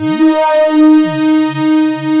OSCAR 10 D#4.wav